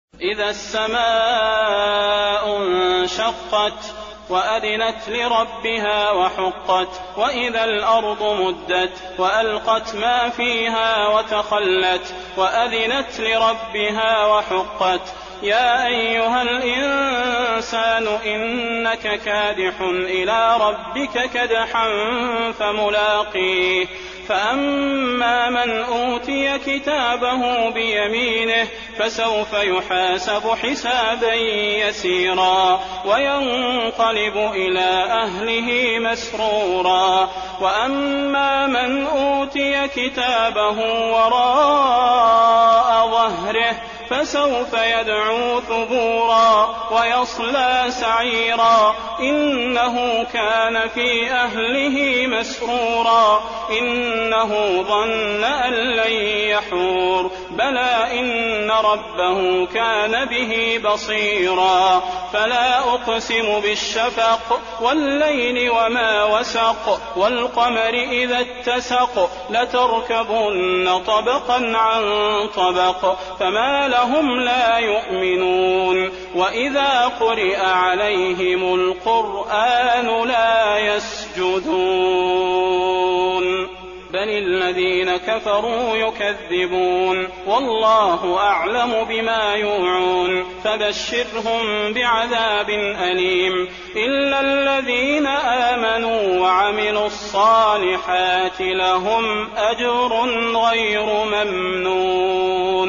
المكان: المسجد النبوي الانشقاق